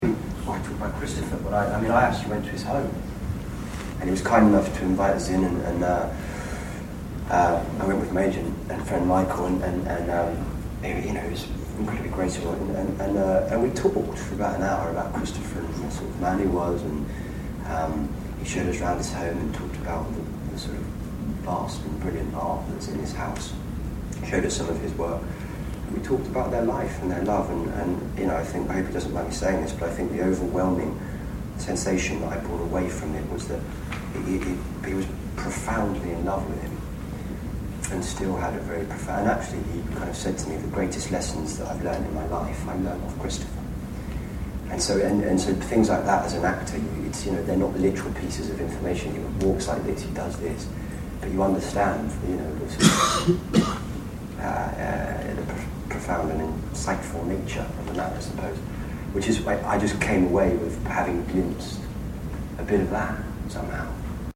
There was a Q&A after the screening with Matt, writer Kevin Elyot and director Geoffrey Sax.
Below are a few short audio extracts of what Matt had to say: